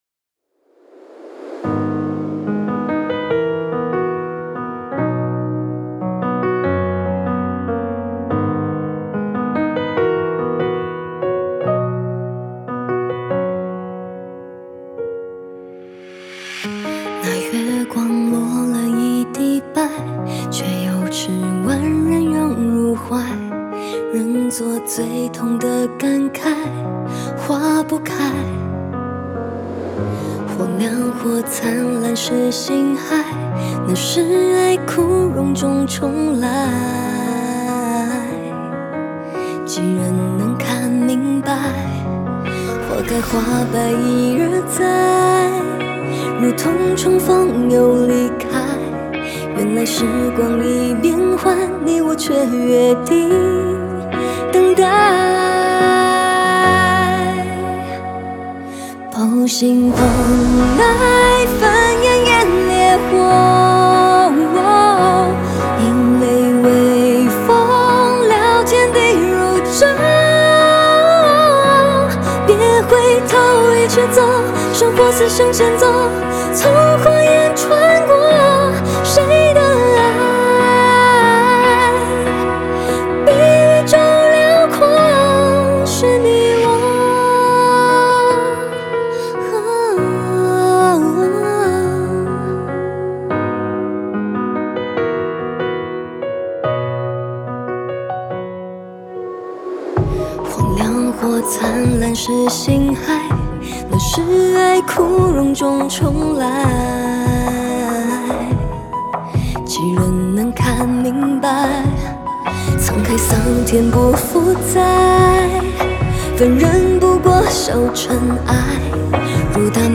Ps：在线试听为压缩音质节选，体验无损音质请下载完整版
吉他
录音棚：Studio21A (北京)
和声